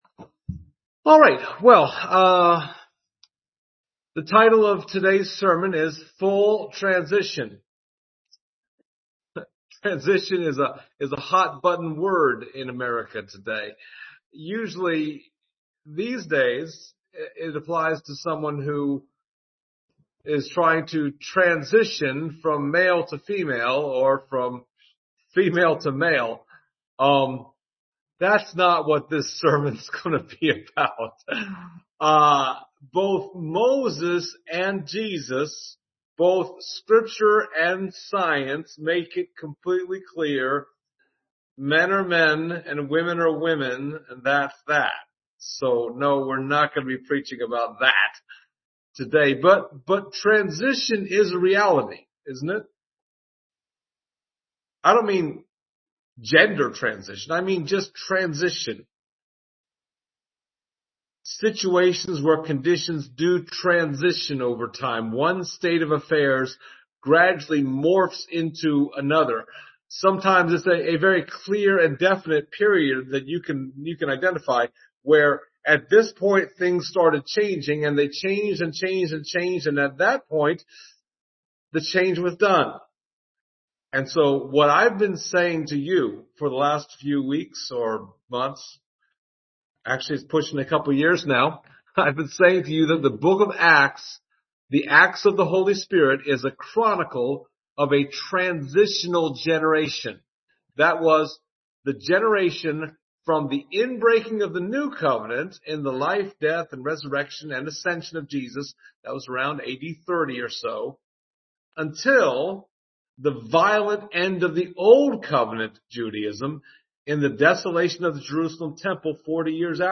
Service Type: Sunday Morning Topics: baptism , holy spirit , John the Baptist , New Covenant